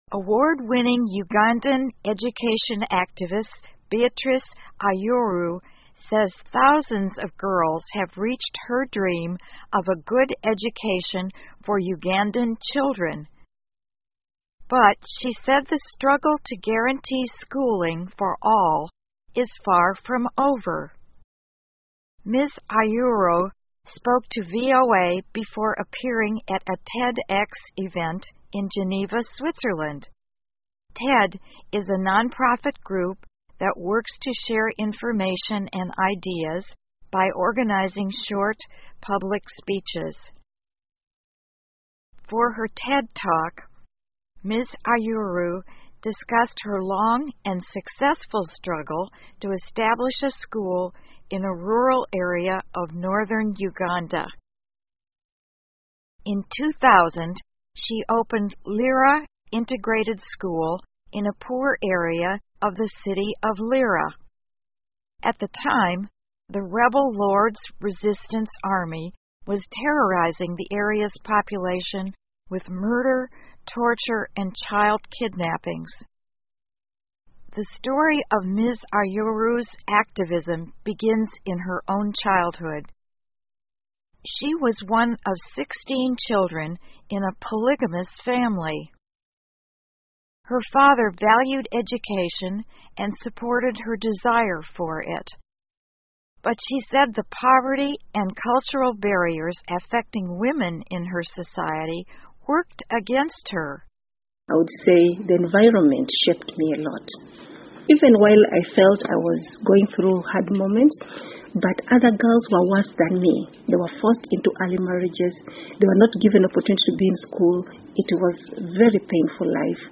VOA慢速英语2015 乌干达的教育活动家称赞女孩儿从教育中获益 听力文件下载—在线英语听力室